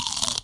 crunchyBite.mp3